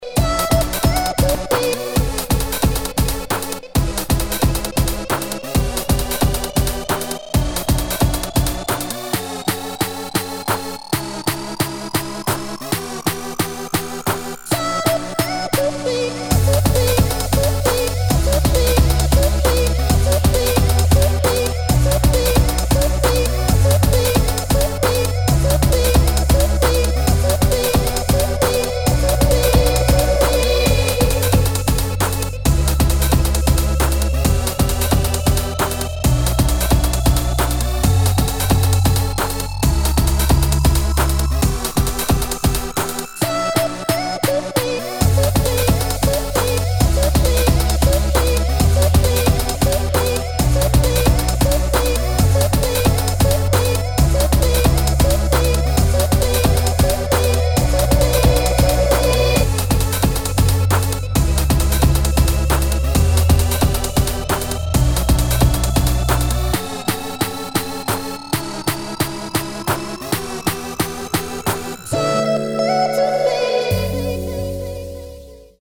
[ DUBSTEP ]